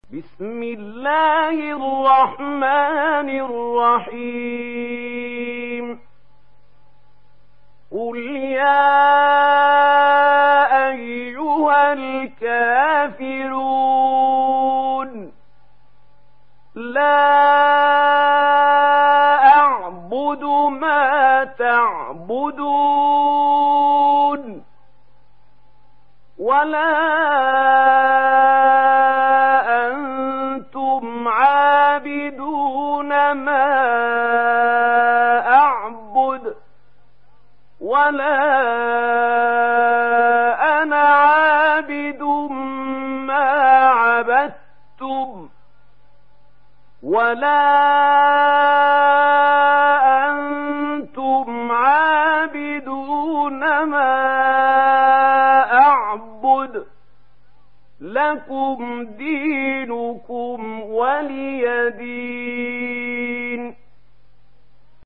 تحميل سورة الكافرون mp3 بصوت محمود خليل الحصري برواية ورش عن نافع, تحميل استماع القرآن الكريم على الجوال mp3 كاملا بروابط مباشرة وسريعة